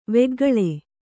♪ veggaḷe